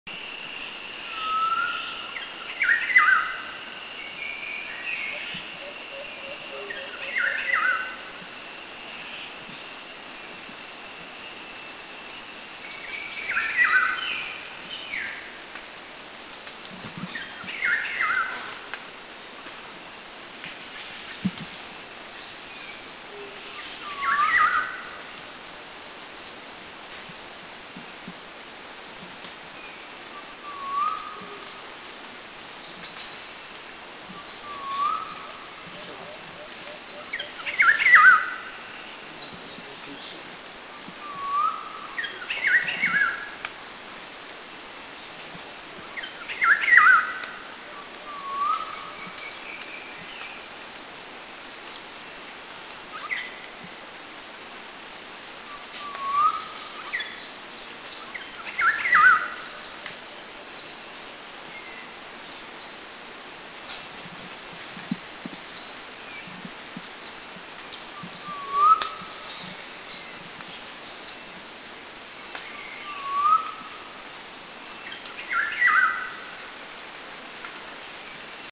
Philippine Oriole   Oriolus steerii  Country endemic
B2A_PhilippineOrioleSongBohol210_SDW.mp3